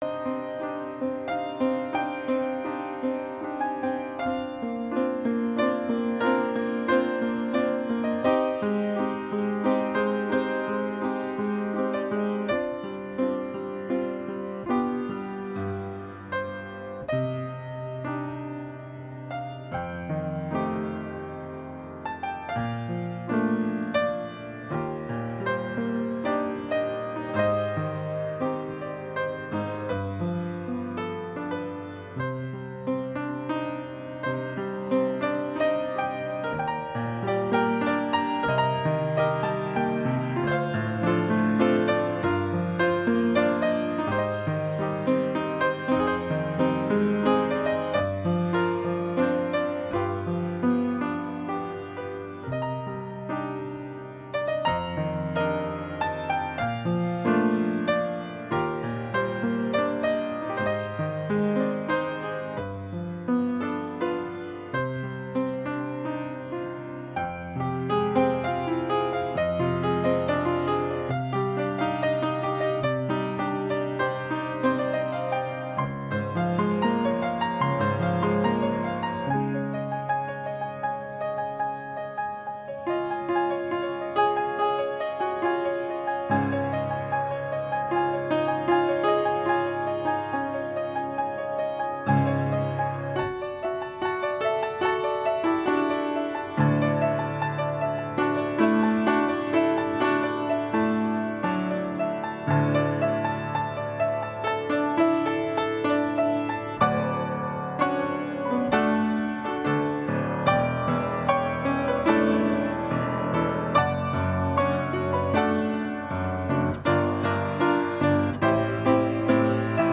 Candle Light Service